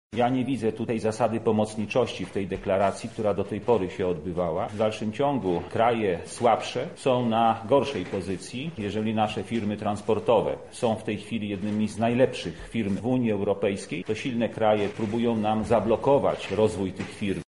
Na ten temat mówi Andrzej Stanisławek, senator z województwa Lubelskiego: